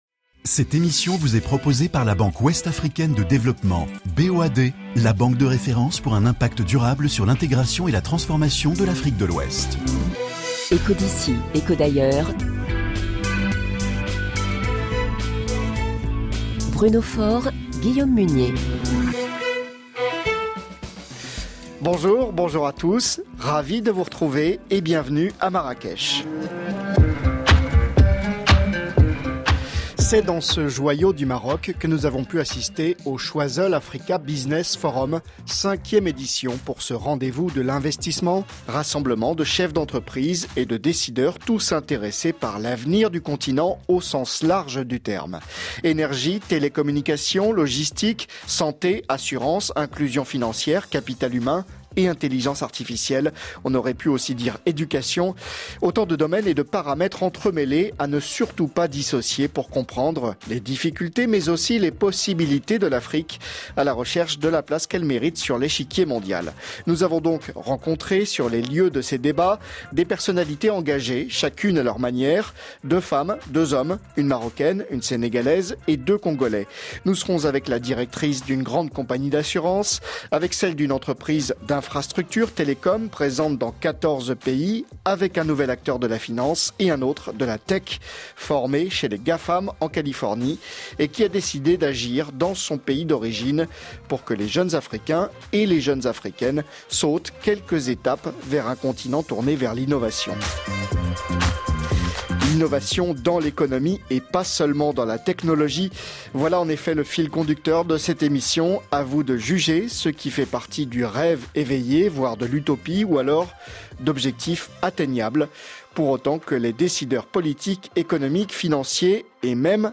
À cette occasion, nous avons pu rencontrer quatre de ces personnalités engagées chacune à leur manière dans la recherche de solutions dans les domaines de l'énergie, les télécommunications, la logistique, la santé, l'assurance, l'inclusion financière, le capital humain, ou encore les nouvelles technologies comme l'intelligence artificielle, le cloud et la cybersécurité.